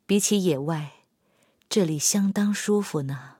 SU-122A司令部语音2.OGG